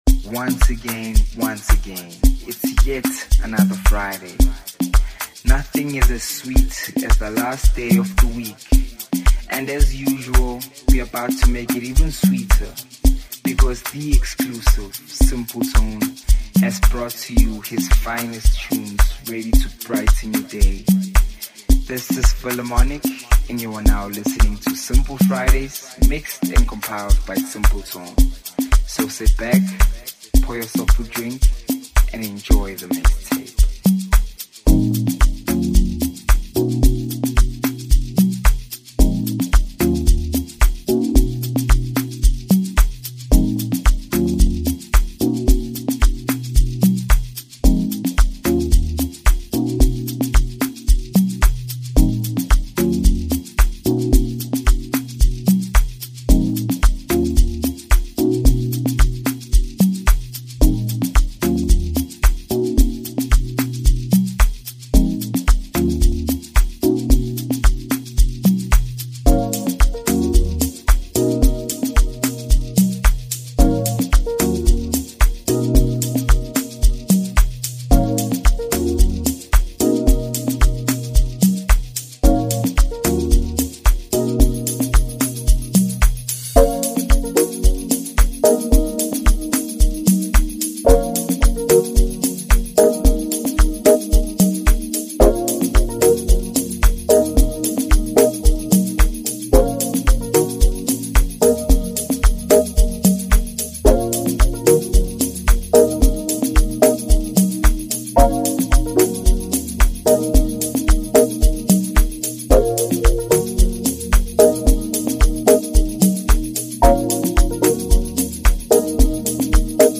The new mixtape features 73 minutes of pure Amapiano vibes.